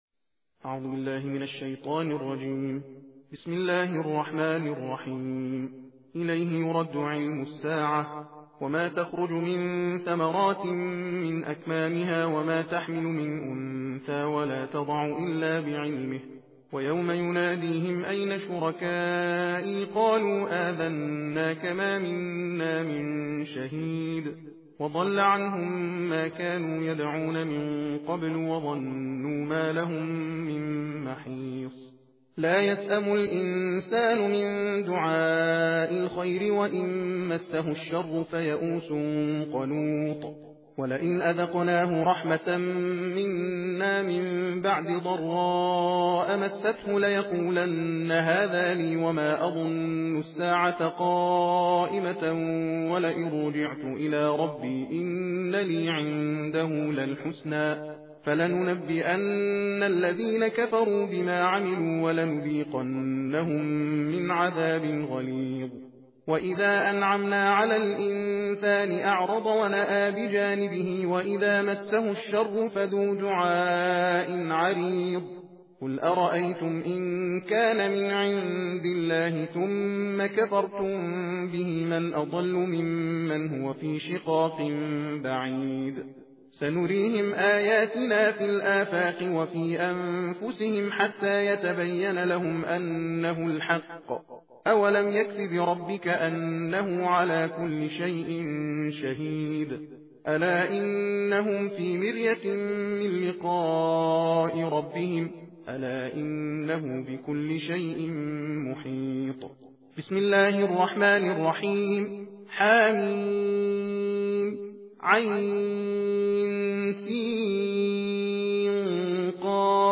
صوت/ تندخوانی جزء بیست و پنجم قرآن کریم
قرائت قرآن